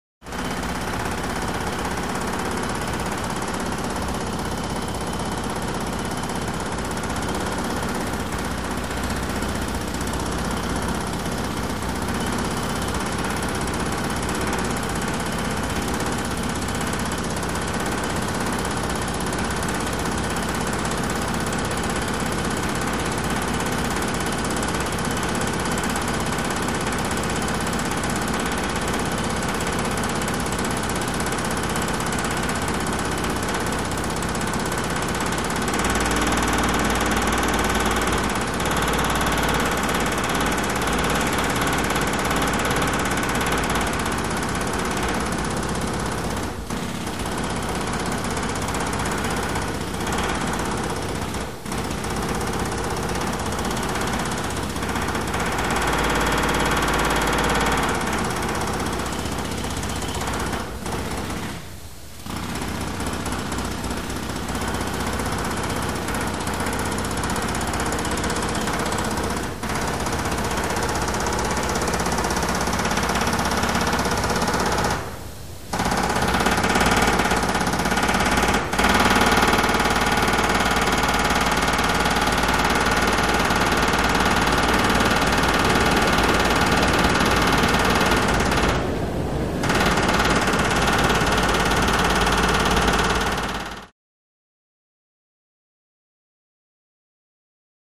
Jackhammer 2; On Bridge Above With Deep Knocking Vibrations. Medium Perspective.